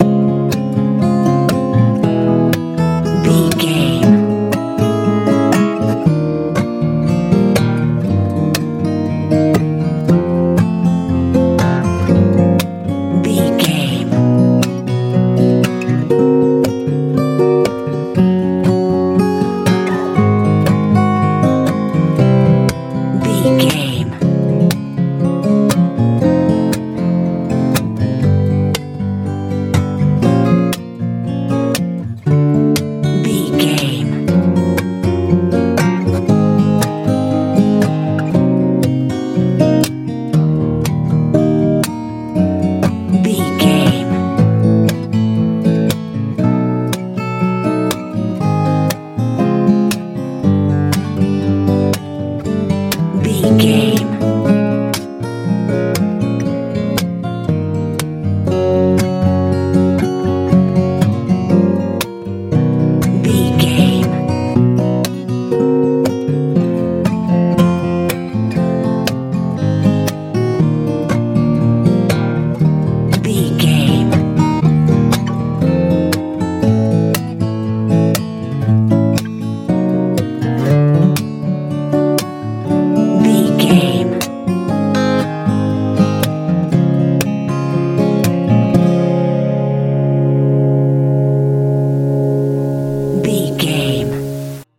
campfire feel
Aeolian/Minor
C♯
acoustic guitar
soothing
soft
smooth
relaxed
mellow
melancholy